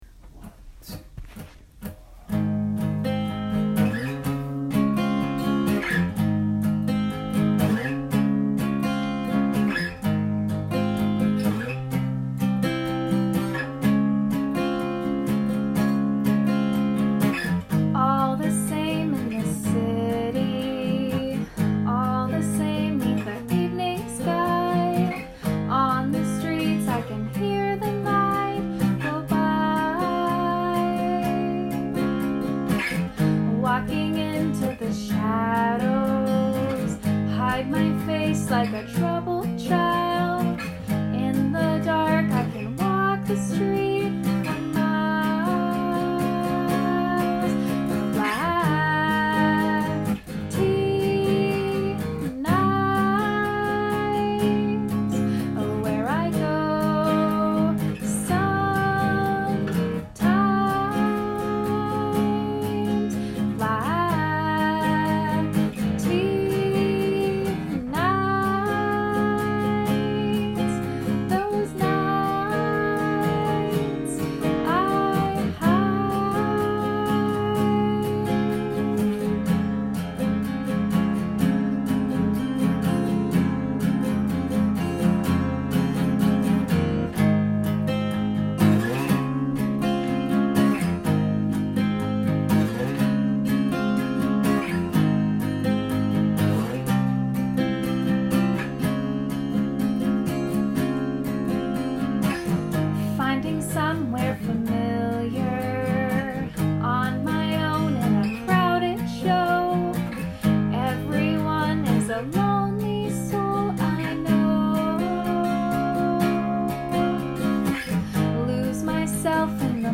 COCO CANARY – A MINNEAPOLIS-BASED INDIE BAND
It is about living in the city and how one spends their time during those lonely nights. The recording below is a rough take and was recorded on my iPhone.